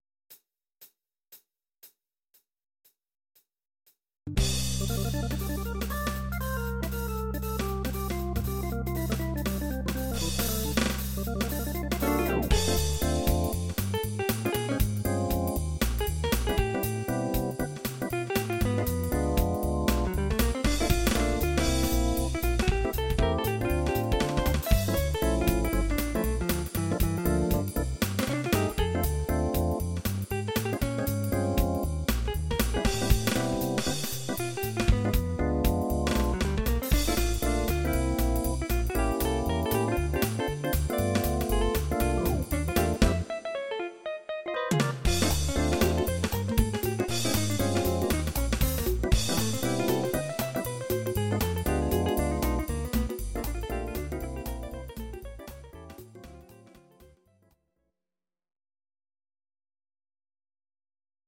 Audio Recordings based on Midi-files
Oldies, Jazz/Big Band, Instrumental, 1950s